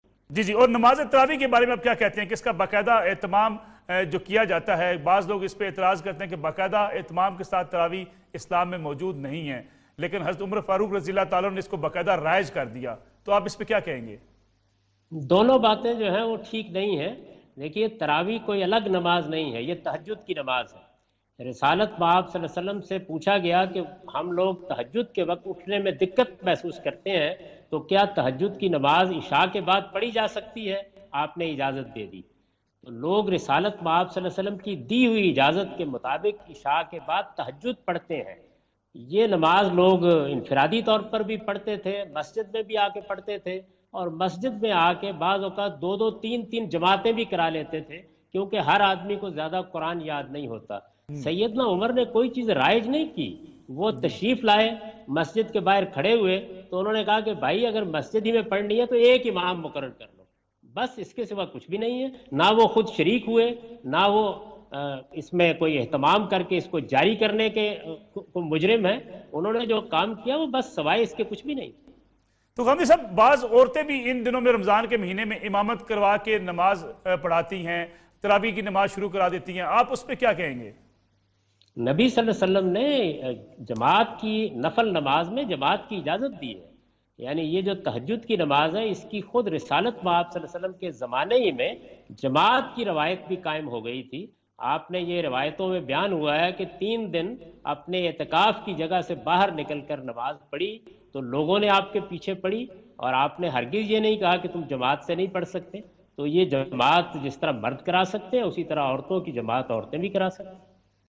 In this program Javed Ahmad Ghamidi answer the question about "The Traveeh Prayer" on Neo News.
جاوید احمد غامدی نیو نیوزکے پروگرام میں "تراویح کی نماز "سے متعلق سوال کا جواب دے رہے ہیں۔